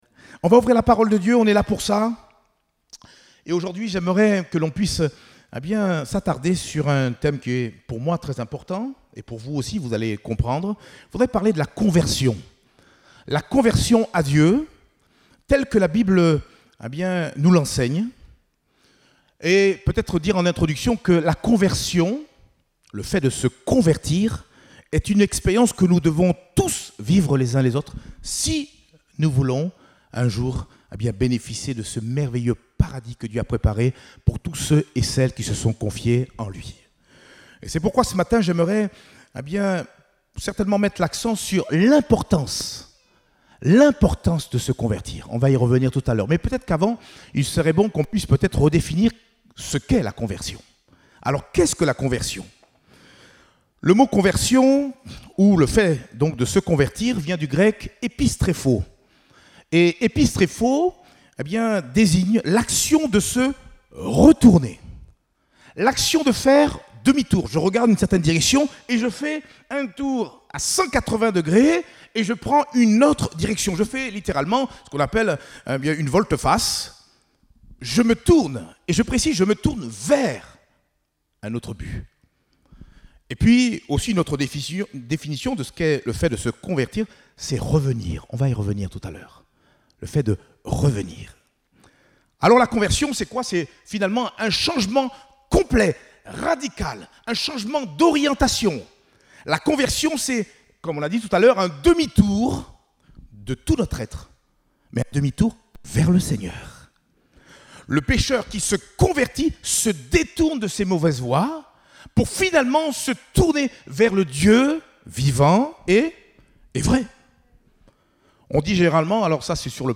Type De Service: Culte Dominical